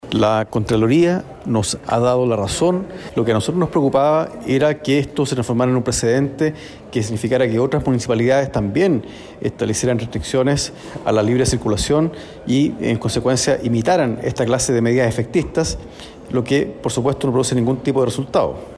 Por otro lado, el diputado del Distrito 7, Luis Cuello, quien en diciembre solicitó un pronunciamiento a la Contraloría por la ordenanza, afirmó que las observaciones que emanó el ente contralor les da la razón.
diputado-cuello-ordenanza.mp3